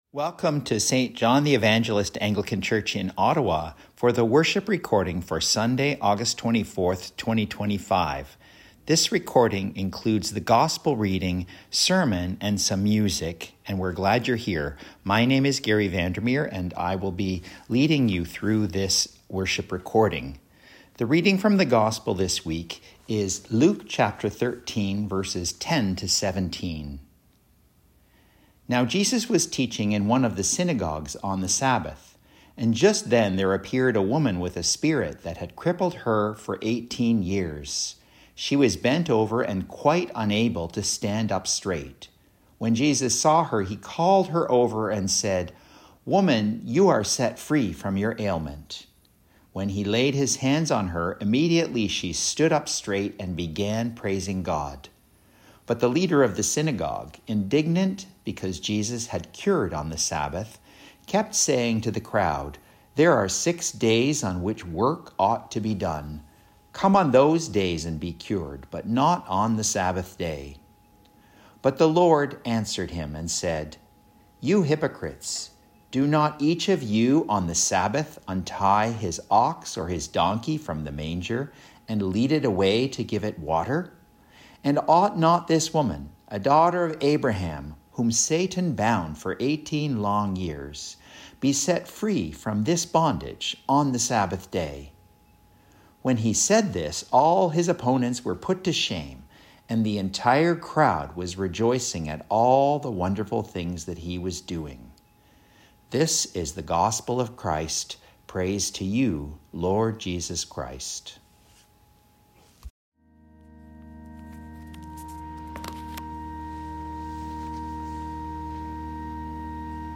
Pride Sermon - Eleventh Sunday after Pentecost